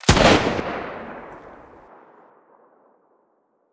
Sniper1_Shoot 02.wav